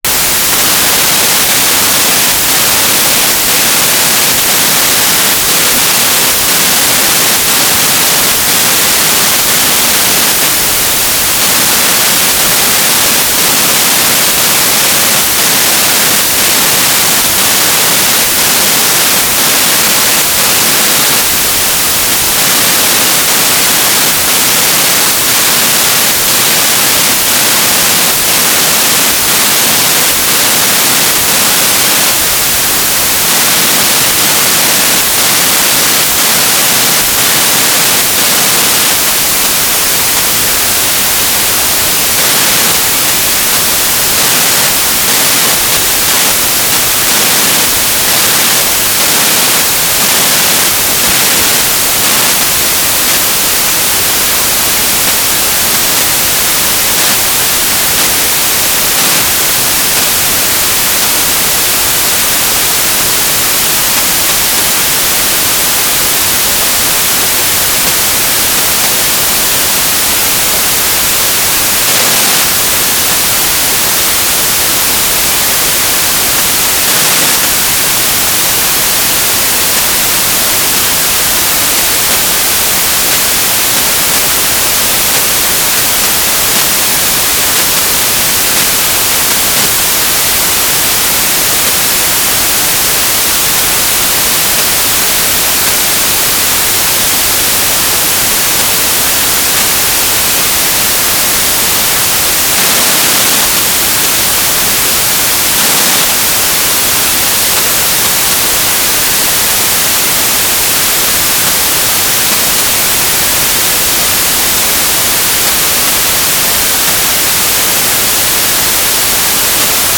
"transmitter_description": "Telemetry",
"transmitter_mode": "FSK",